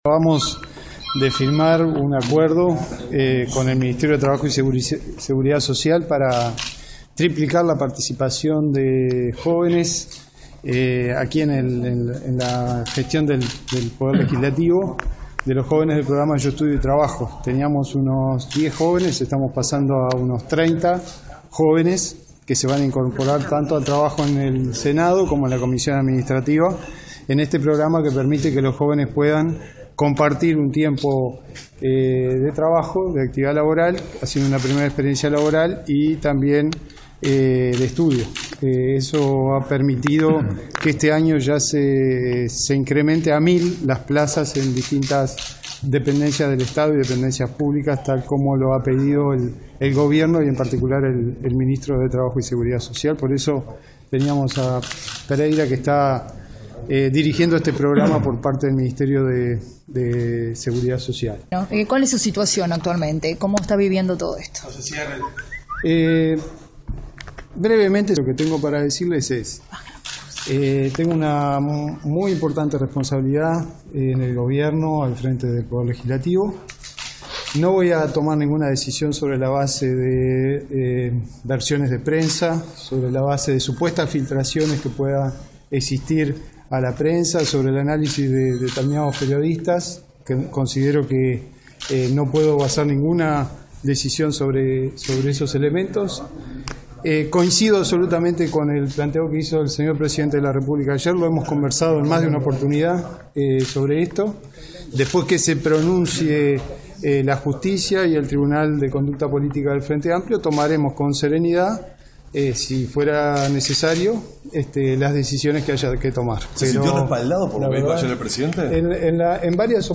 Sendic dijo a la prensa que seguirá al frente de sus responsabilidades en el Parlamento.